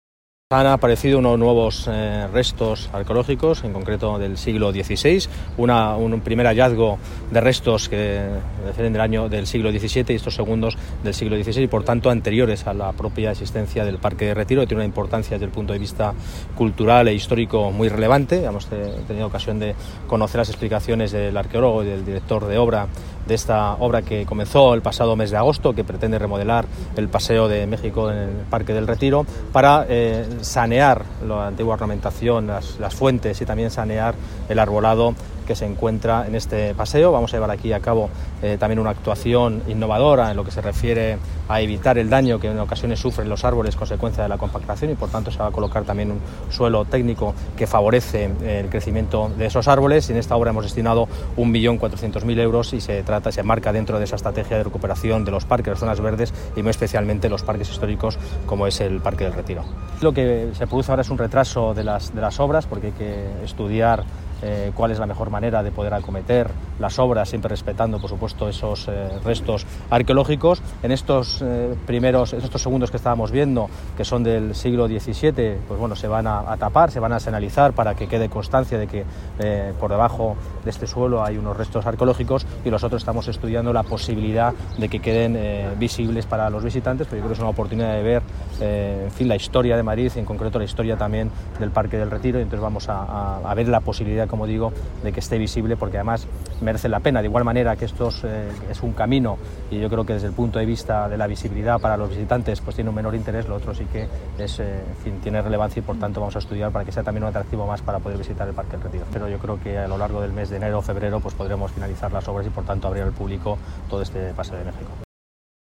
Nueva ventana:El delegado de Medio Ambiente y Movilidad, Borja Carabante, explica los detalles de los nuevos hallazgos arqueológicos encontrados en el paseo de México